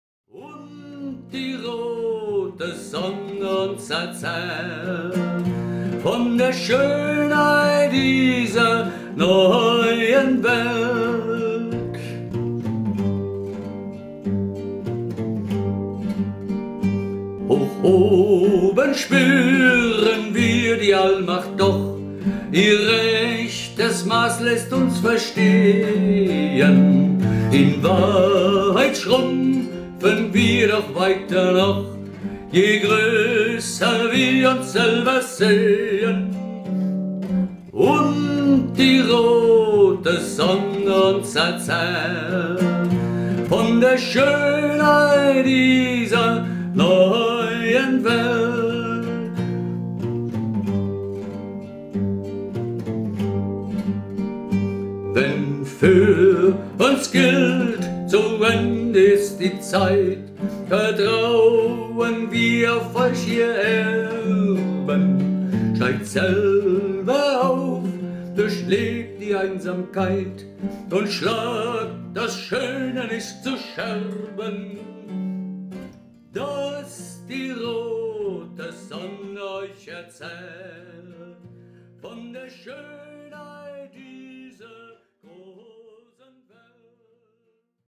in g-moll / 3. Bund e-moll